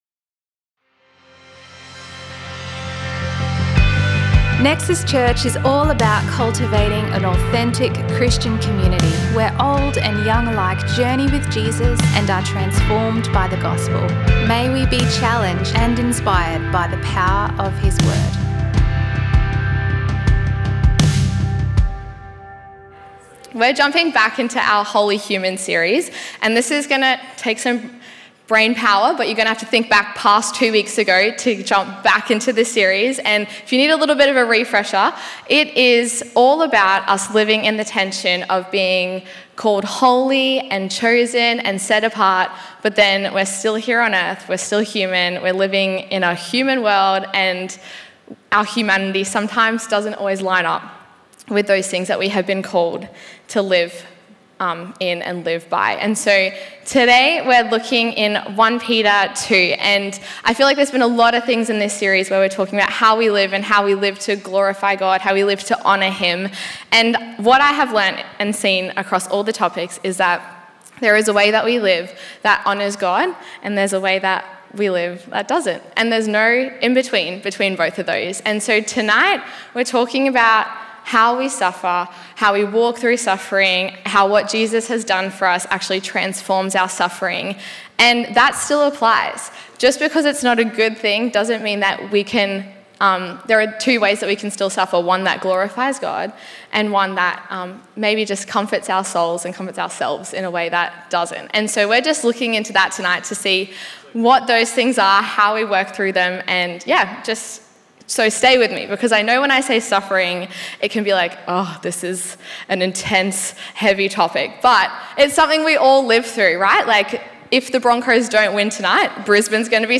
A selection of messages from Nexus Church in Brisbane, Australia.